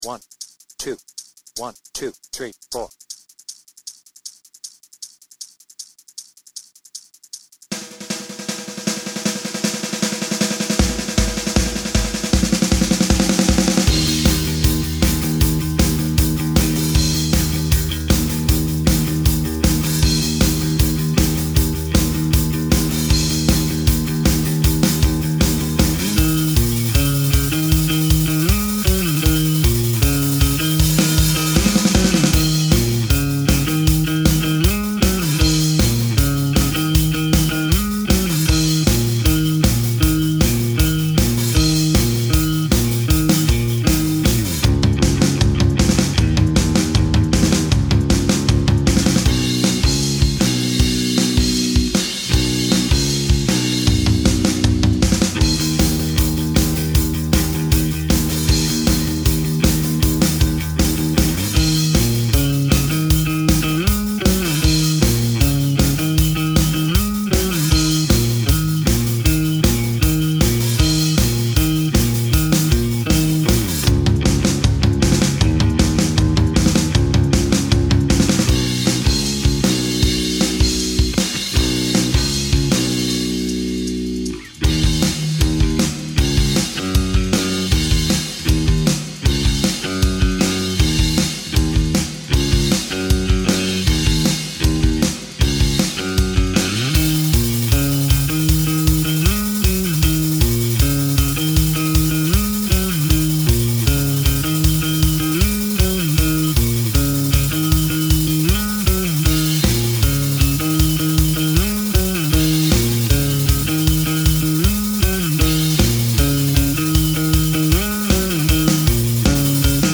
BPM : 156
Tuning : Eb
Without vocals
Based on the studio version